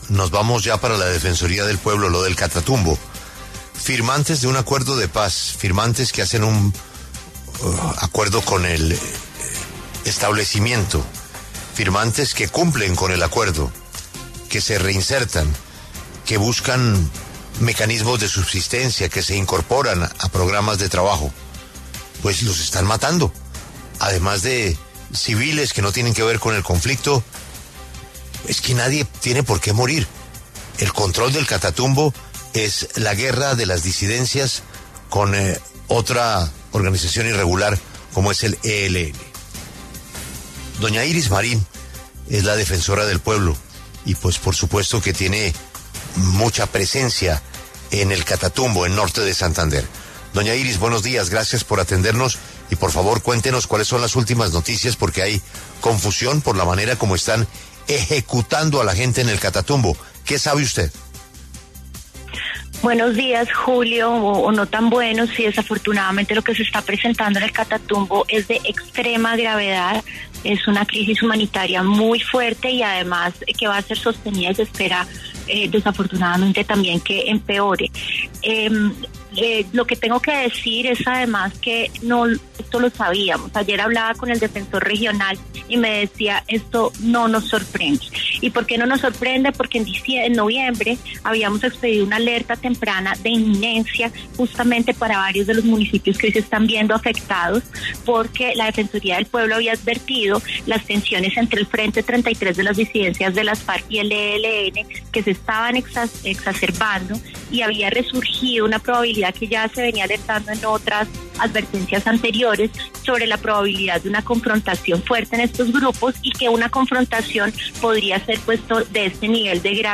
A propósito de esto, la defensora del Pueblo, Iris Marín, entregó detalles de lo sucedido en los micrófonos de La W.